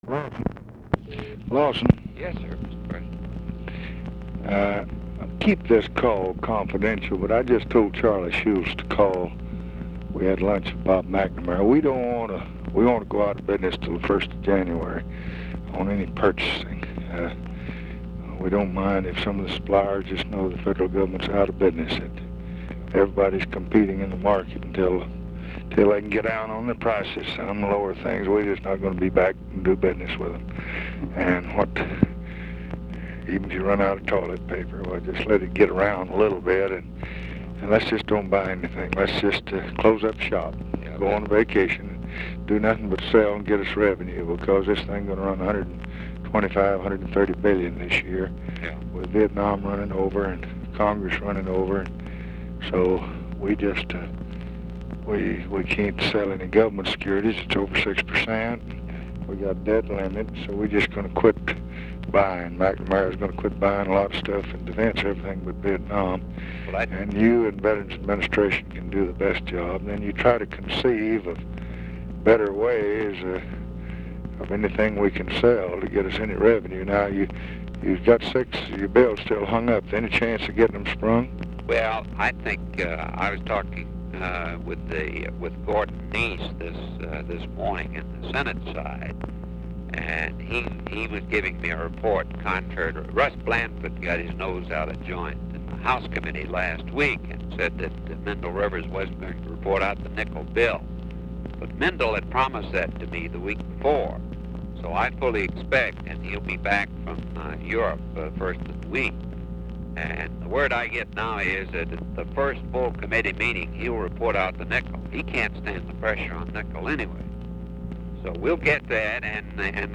Conversation with LAWSON KNOTT, September 10, 1966
Secret White House Tapes